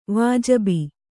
♪ vājabi